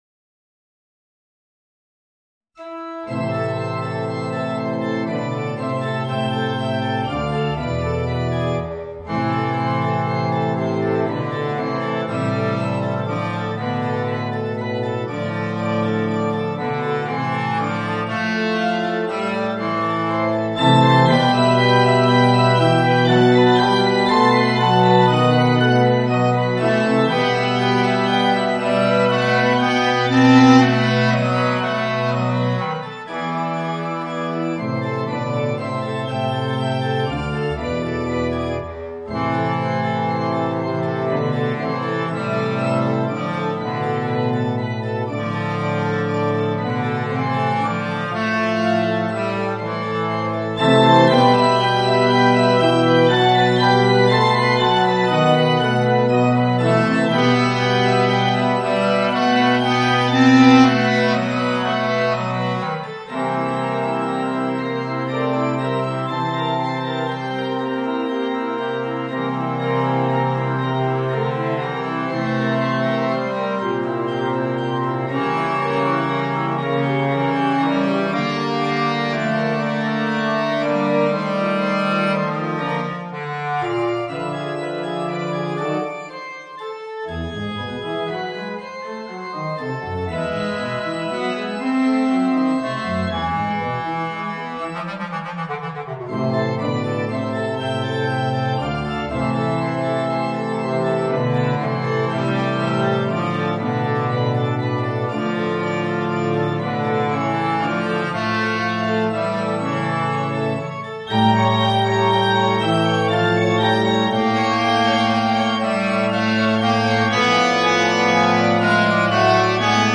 Bassklarinette & Klavier